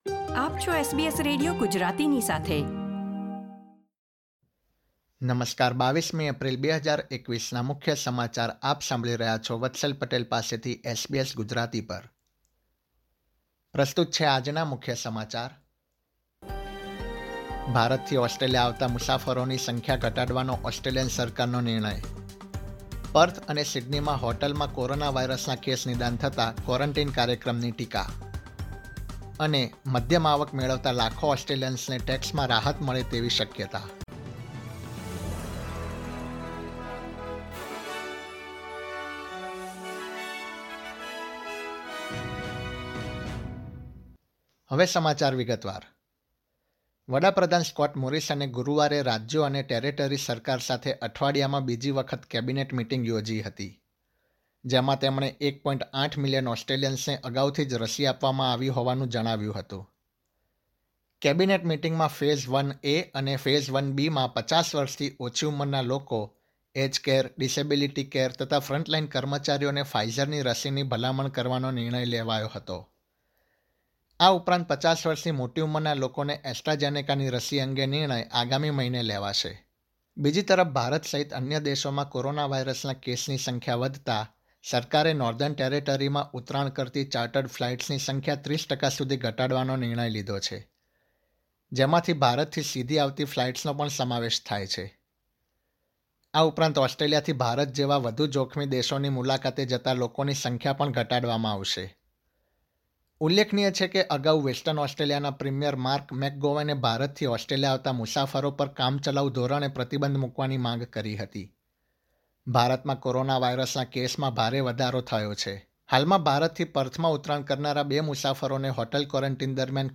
SBS Gujarati News Bulletin 22 April 2021